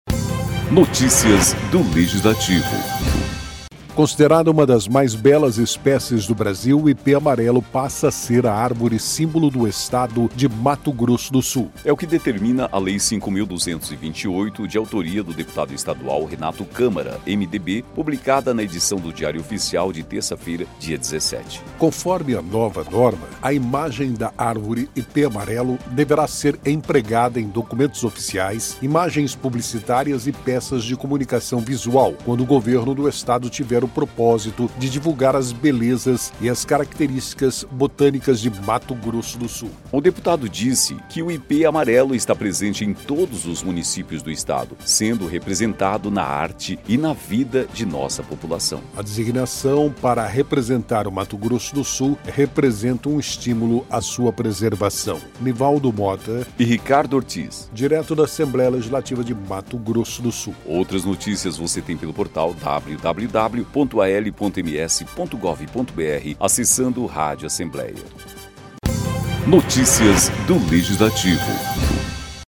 “O Ipê-Amarelo está presente em todos os municípios do Estado, sendo representado na arte e na vida de nossa população. A designação para representar o MS representa um estímulo à sua preservação”, destacou Câmara.